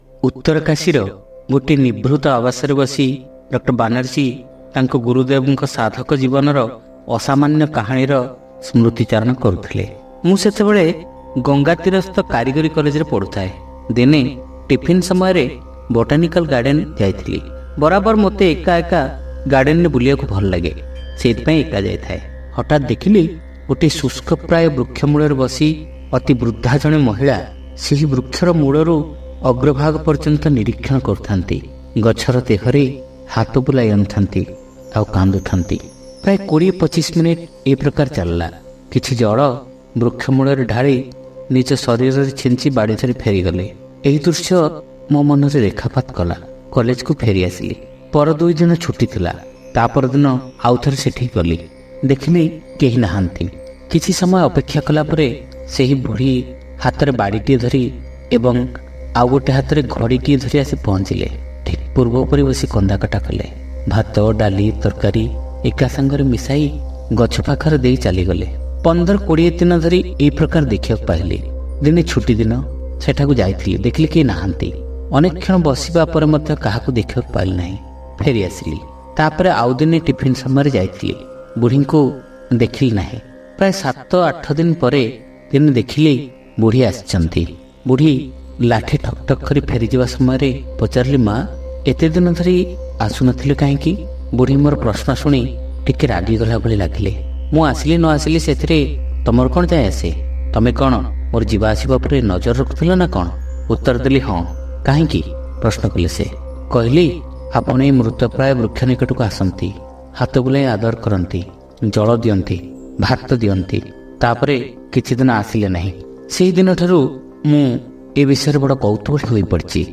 ଶ୍ରାବ୍ୟ ଗଳ୍ପ : ବୃକ୍ଷ ସହିତ ଆମର ସମ୍ପର୍କ - ଆଲୋକରେ ଅଭିଷେକ